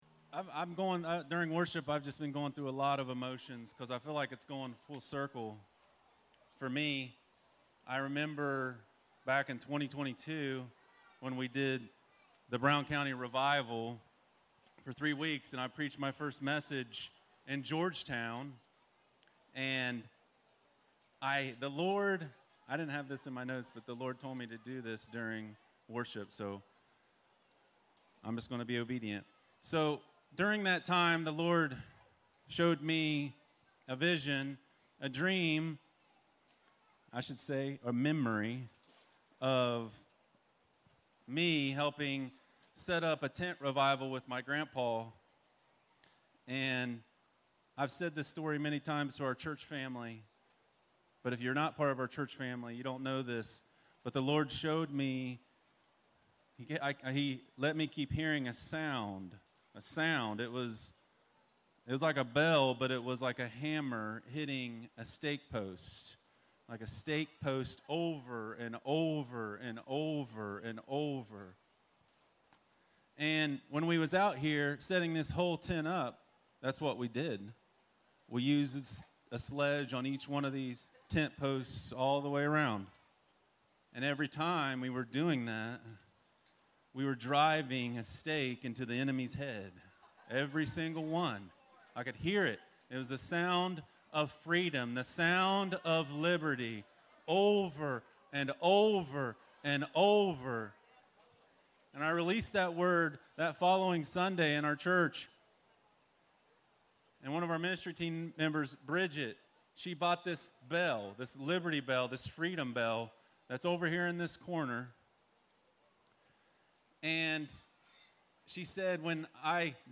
REAL ONES: From Darkness to Light - Stand Alone Messages ~ Free People Church: AUDIO Sermons Podcast